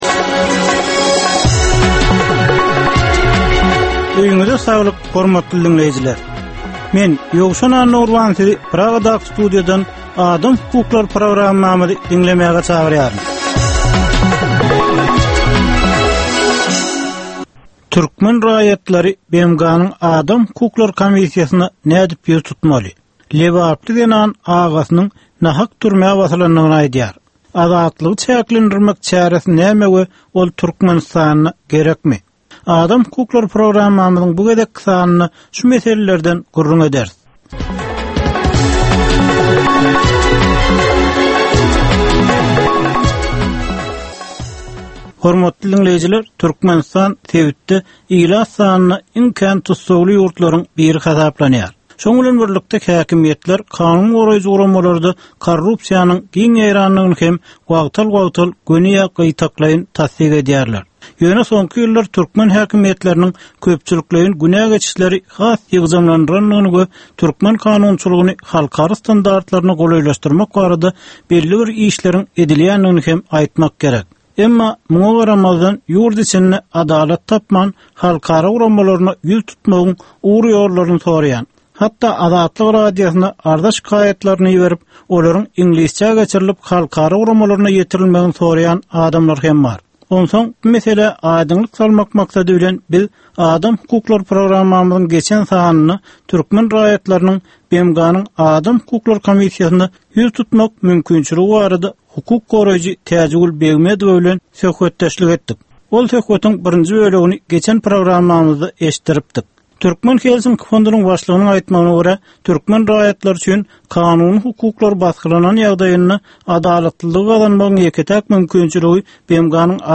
Türkmenistandaky ynsan hukuklarynyň meseleleri barada 15 minutlyk ýörite programma. Bu programmada ynsan hukuklary bilen baglanyşykly anyk meselelere, problemalara, hadysalara we wakalara syn berilýar, söhbetdeşlikler we diskussiýalar gurnalýar.